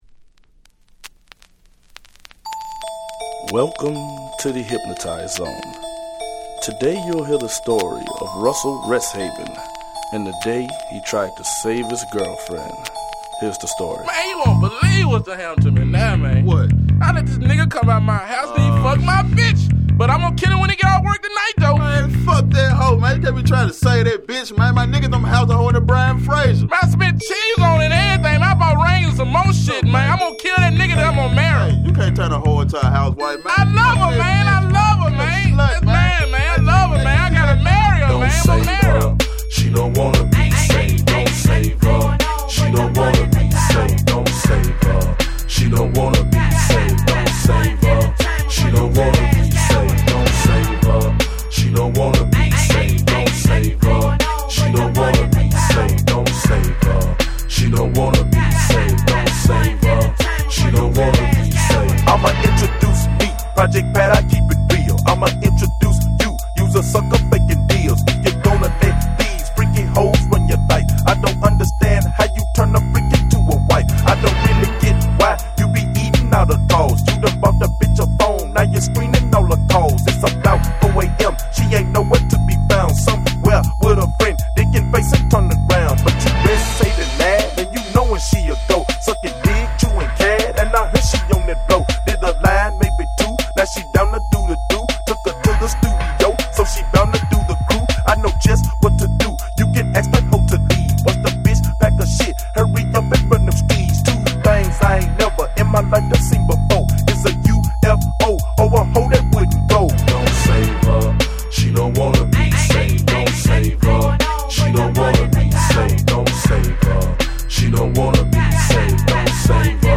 01' Smash Hit Southern Hip Hop !!